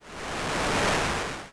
tureher_toraufschieben.wav